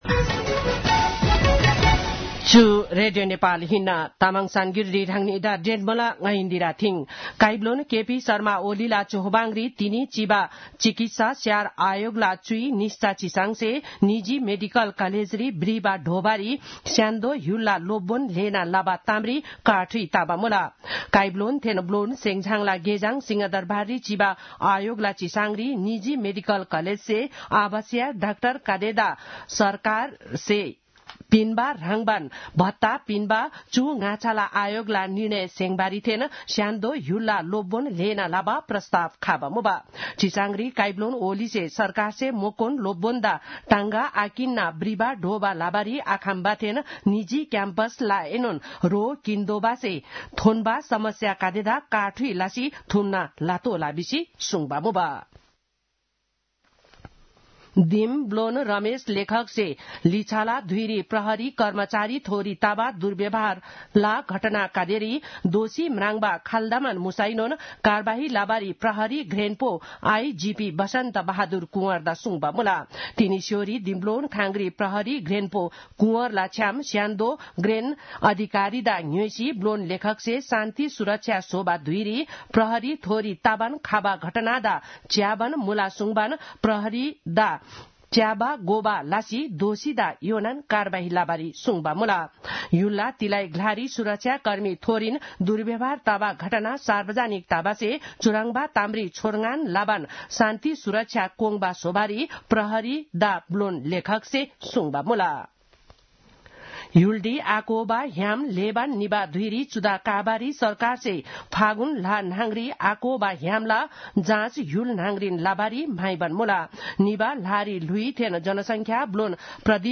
तामाङ भाषाको समाचार : १ फागुन , २०८१
Tamang-news-10-30.mp3